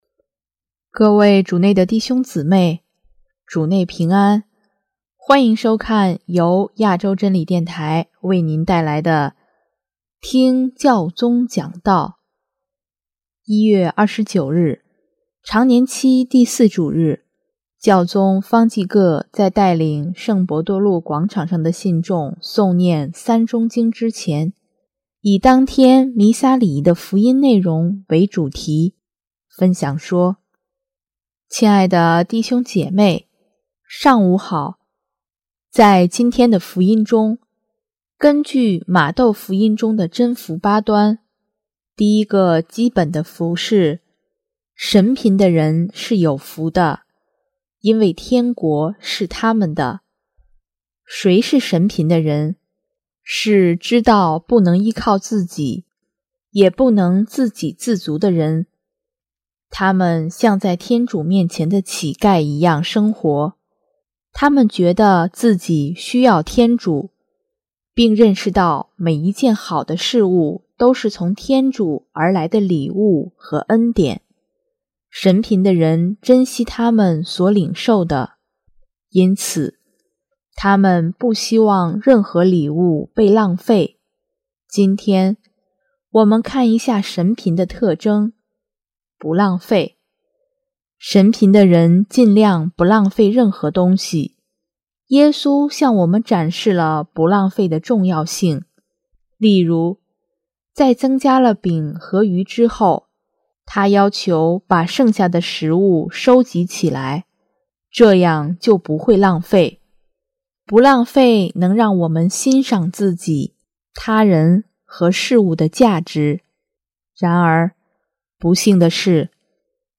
【听教宗讲道】|不浪费，亦不“丢弃”人
1月29日，常年期第四主日，教宗方济各在带领圣伯多禄广场上的信众诵念《三钟经》之前，以当天弥撒礼仪的福音内容为主题，分享说：